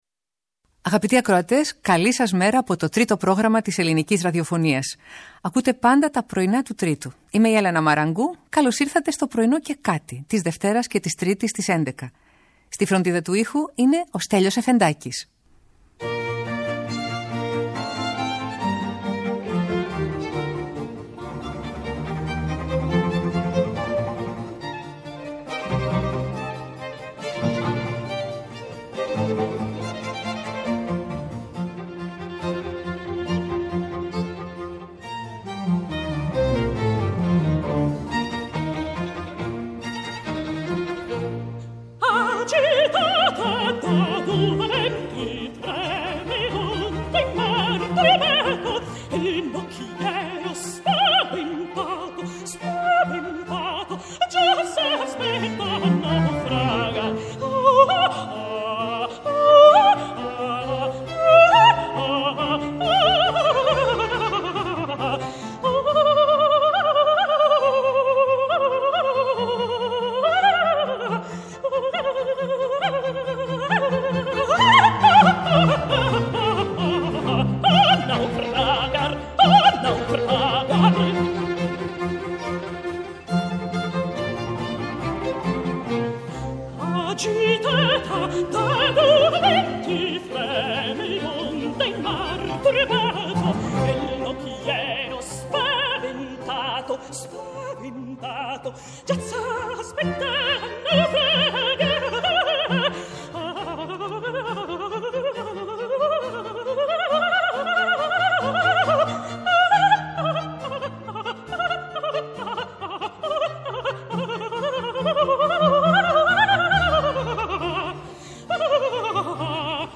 Ερμηνείες από όλες τις εποχές και μερικές ανατροπές συνοδεύουν τις μικρές και μεγάλες εικόνες της ημέρας.